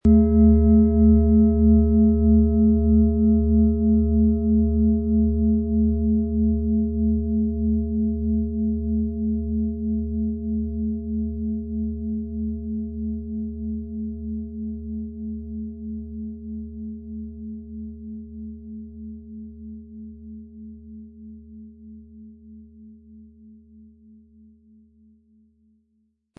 Klangschale, in überlieferter Weise von Hand getrieben.
Um den Originalton der Schale anzuhören, gehen Sie bitte zu unserer Klangaufnahme unter dem Produktbild.